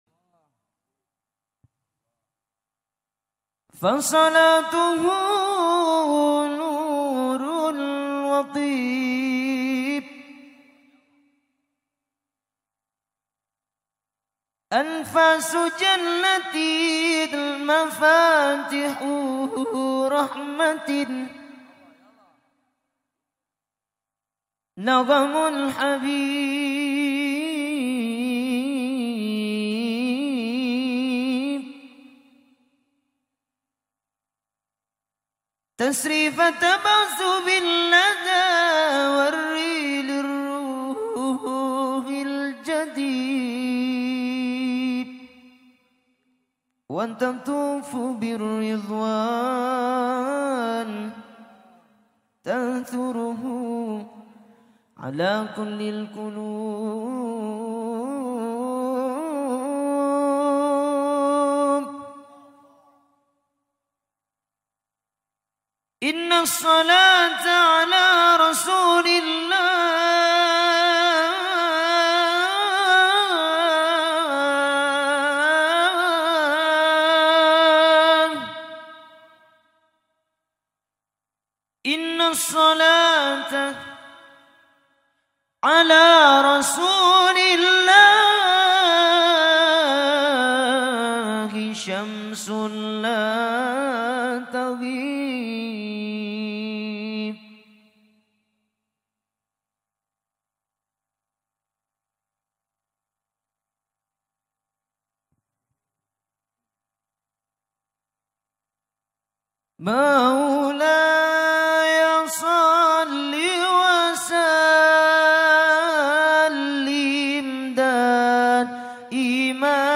New Sholawat